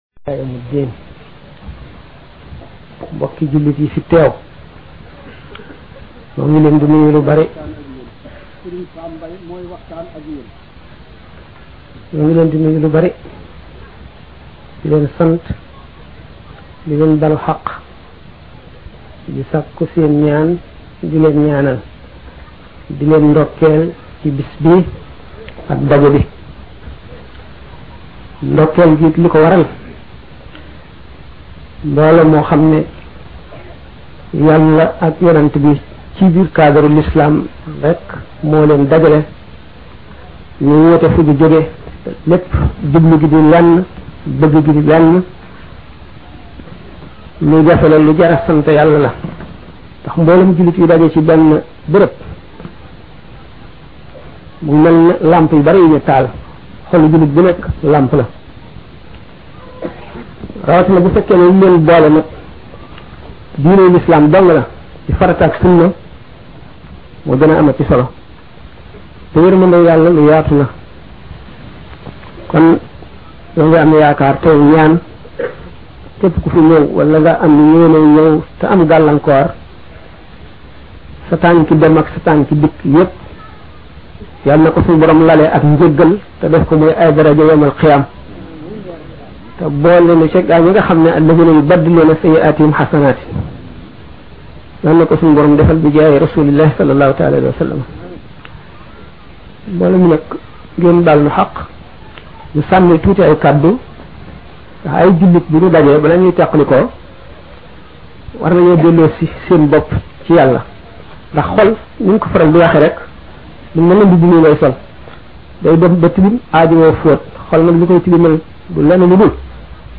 Gamou Bele 1997